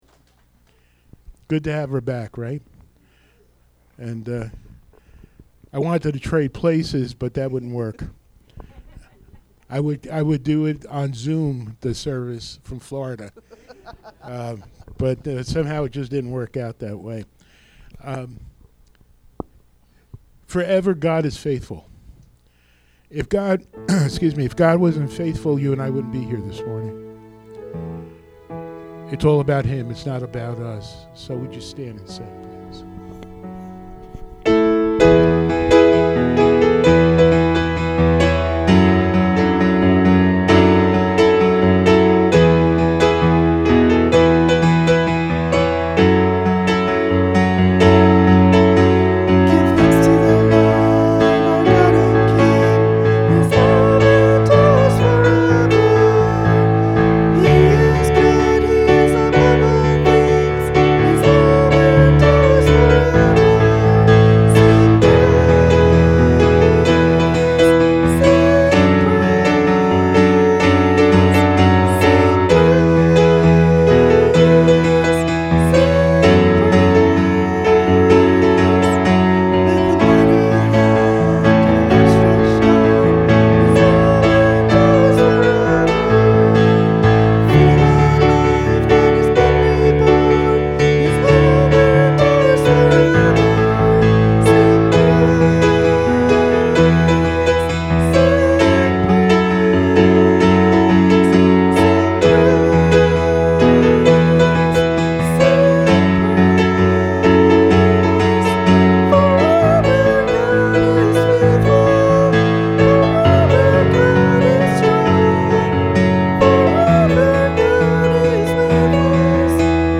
“Learn True Humility!” John 13:1-17 Communion Sunday | Crossbridge Community Church